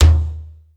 • Verby Tom Sound D# Key 02.wav
Royality free tom sound tuned to the D# note. Loudest frequency: 573Hz
verby-tom-sound-d-sharp-key-02-rkZ.wav